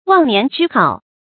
忘年之好 wàng nián zhī hǎo
忘年之好发音